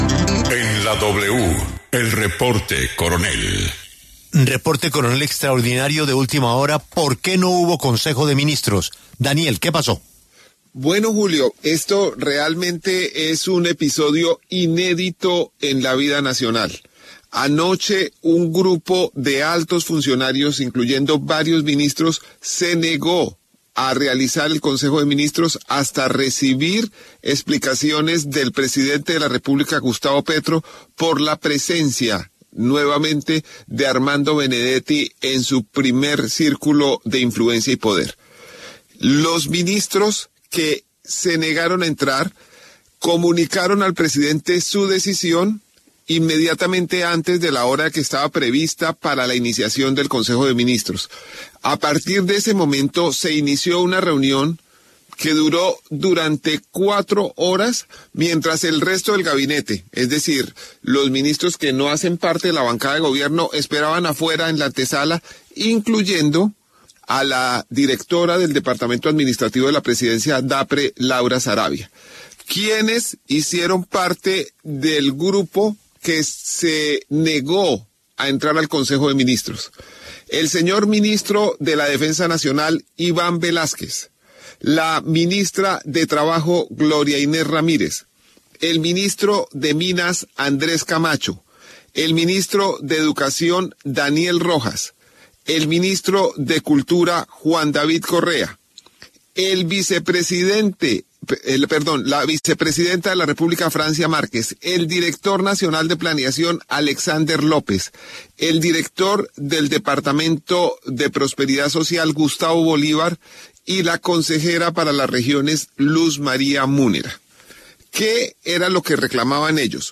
En El Reporte Coronell de La W, el periodista Daniel Coronell reveló los detalles de un episodio inédito en la vida nacional, ocurrido el pasado 25 de noviembre en horas de la noche, cuando un grupo de altos funcionarios –incluyendo varios ministros– se negó a realizar el Consejo de Ministros hasta recibir explicaciones del presidente Gustavo Petro por el regreso de Armando Benedetti a su primer círculo de influencia y poder.